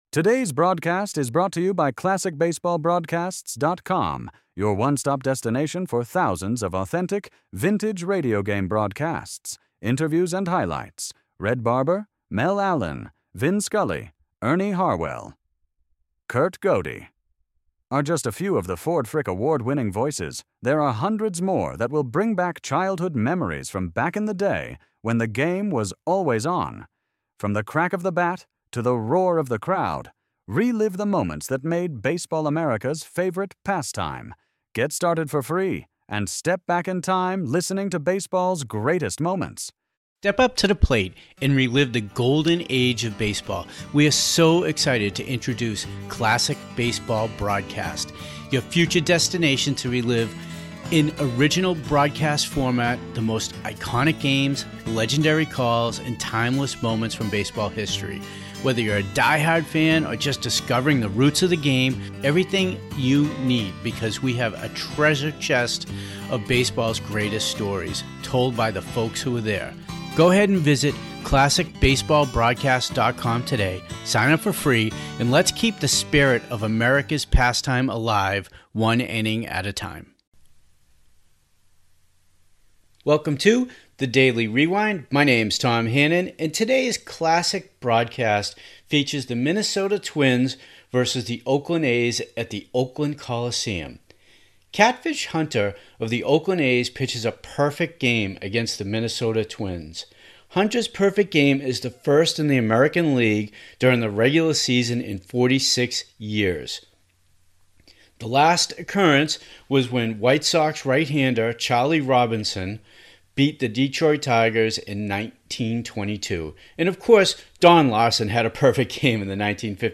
Catfish Hunter is Perfect May 8 1968 - 9th Inning Broadcast